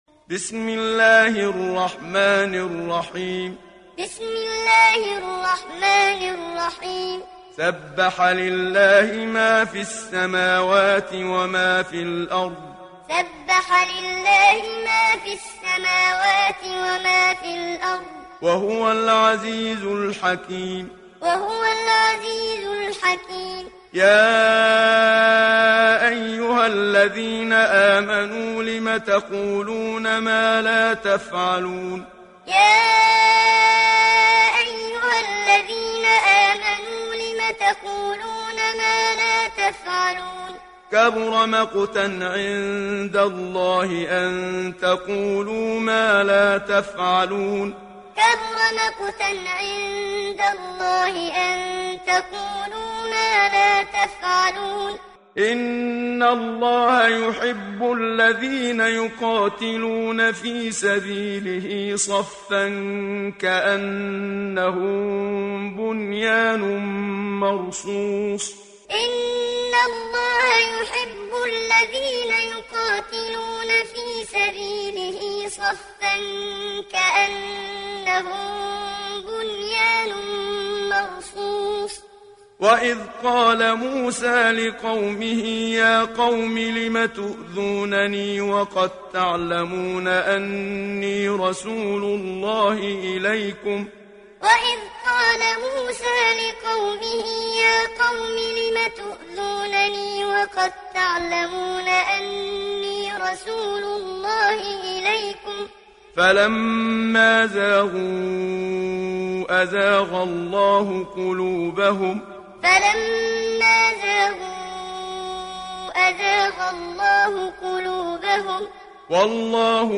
دانلود سوره الصف mp3 محمد صديق المنشاوي معلم روایت حفص از عاصم, قرآن را دانلود کنید و گوش کن mp3 ، لینک مستقیم کامل
دانلود سوره الصف محمد صديق المنشاوي معلم